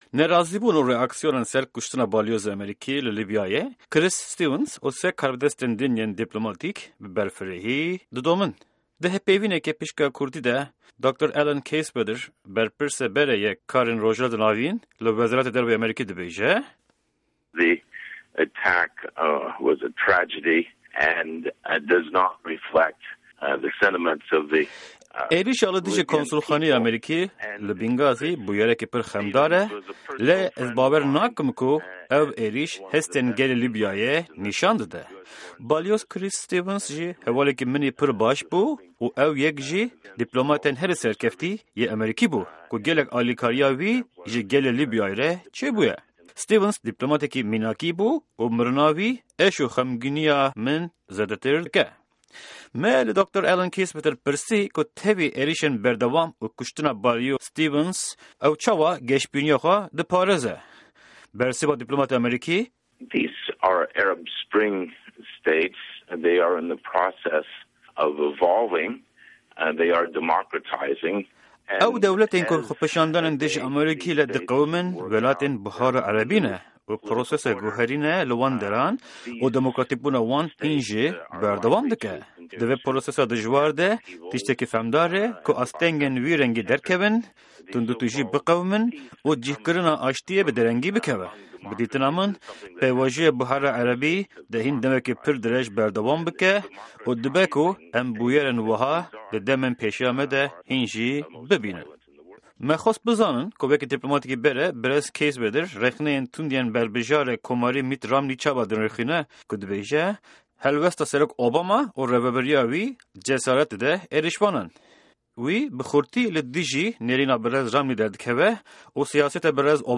Di hevpeyvîneke Pişka Kurdî de, Dr. Allen Keiswetter, berpirsê berê yê karên Rojhilata Navîn li Wezareta Derve kuştina balyozê Amerîkî li Lîbyayê Chris Stevens û 3 karbidestên din yên dîplomatîk şîrove dike